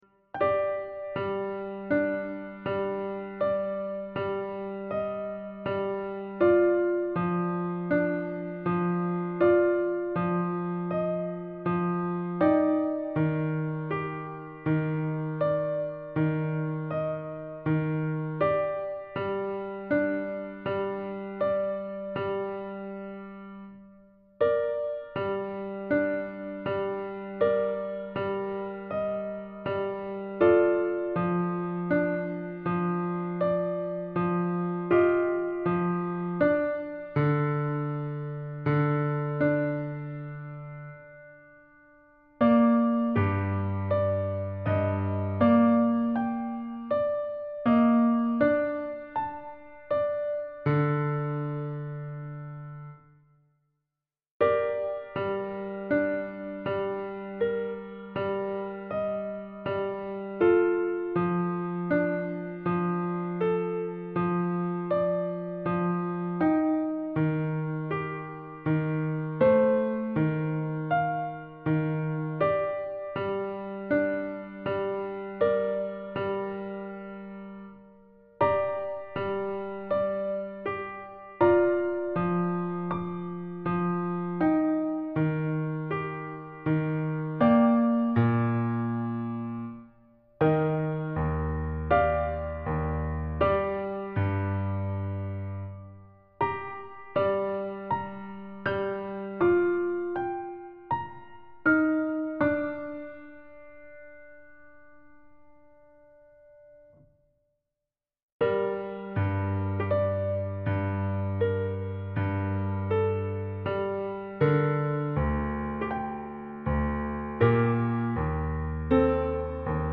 Instrument: piano solo.